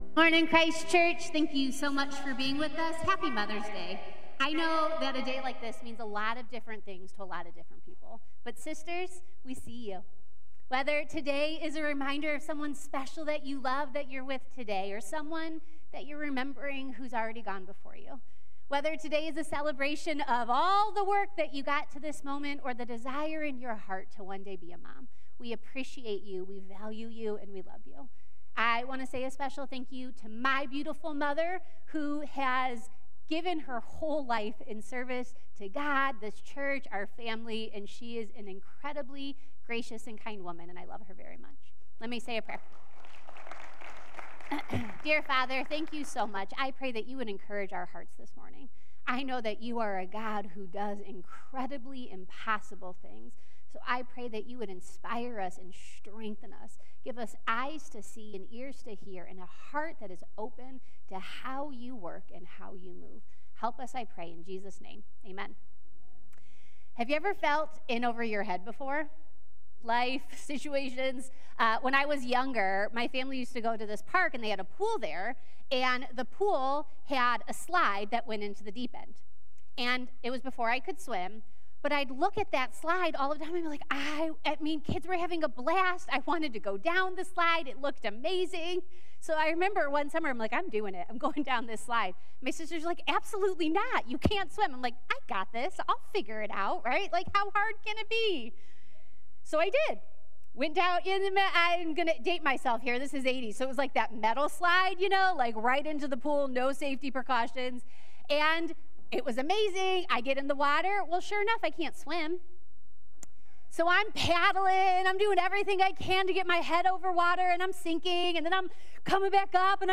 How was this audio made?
Columbia Station Campus